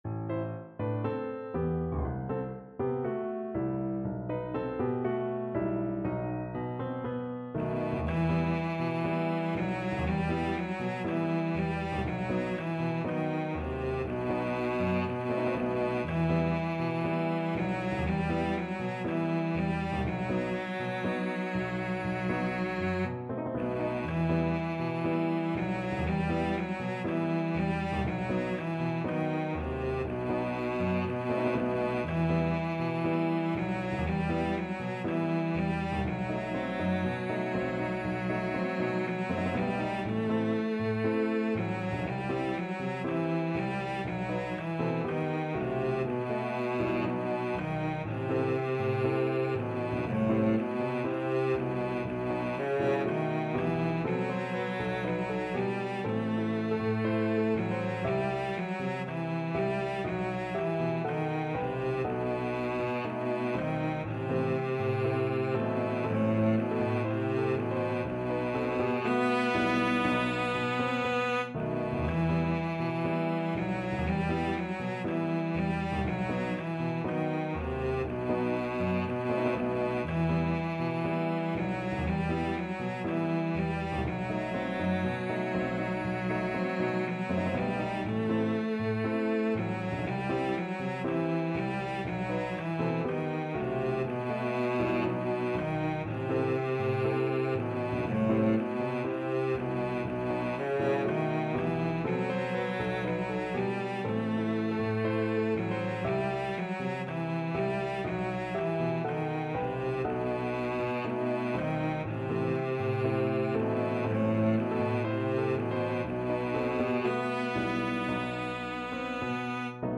Cello
G major (Sounding Pitch) (View more G major Music for Cello )
Slow two in a bar =c.60
2/2 (View more 2/2 Music)
Traditional (View more Traditional Cello Music)